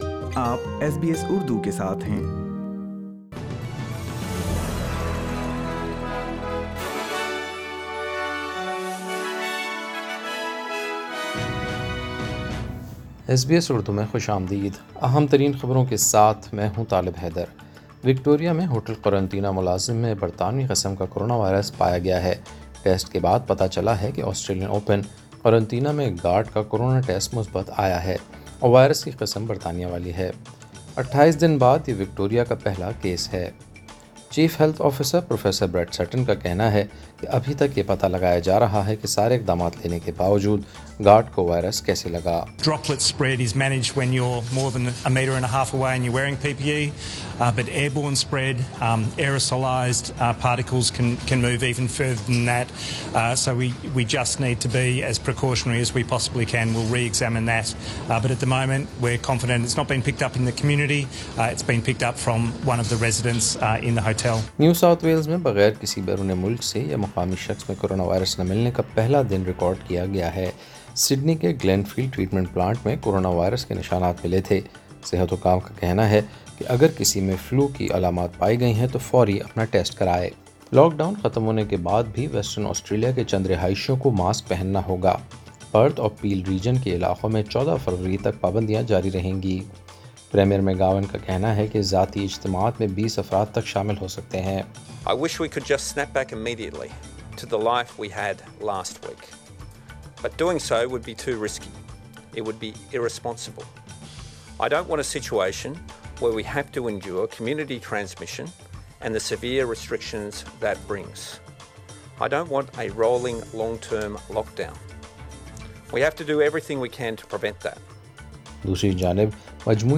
وکٹورین ہوٹل قرنطینہ گارڈ میں برطانیہ والی کرونا وائرس قسم پائی گئی۔ پانچ دن کے لاک ڈاون کے بعد بھی پرتھ کے رہاشئیں کو ماسک پہننا ہوگا۔ سنیئے خبریں اردو میں۔